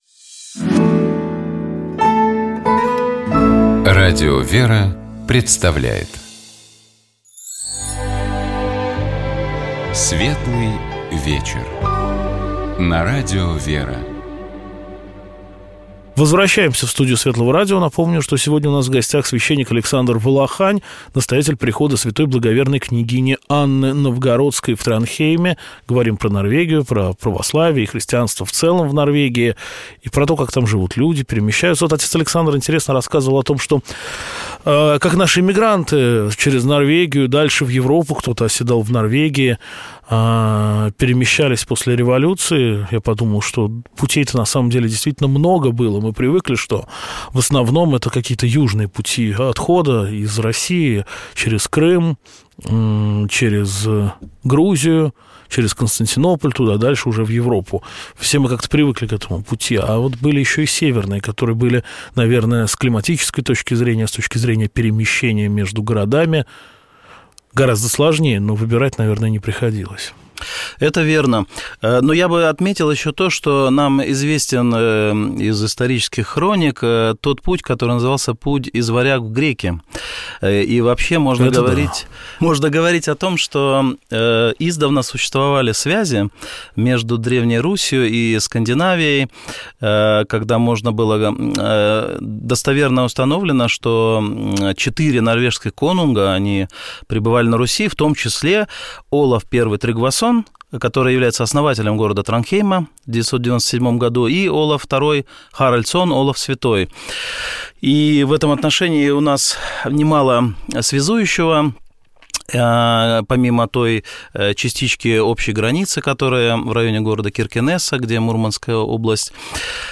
Ближайший час вместе с нами и с вами здесь, в этой студии